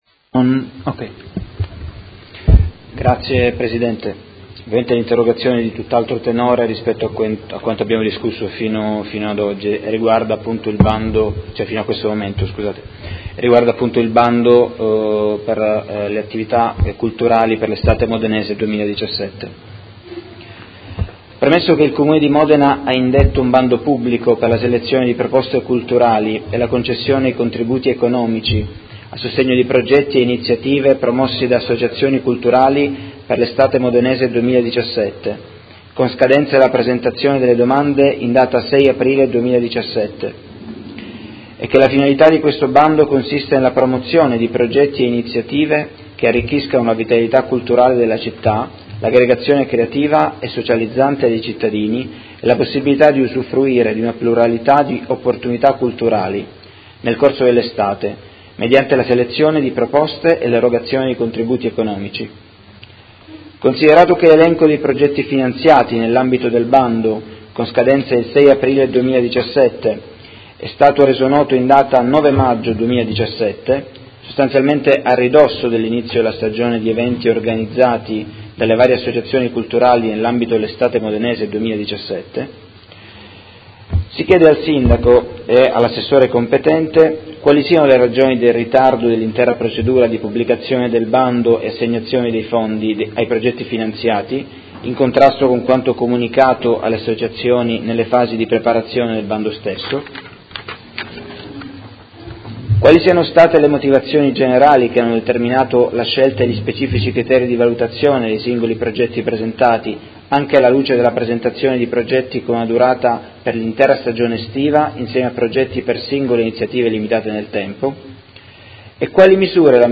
Tommaso Fasano — Sito Audio Consiglio Comunale
Seduta del 25/05/2017 Interrogazione del Consigliere Fasano (PD) avente per oggetto: Bando Estate Modenese 2017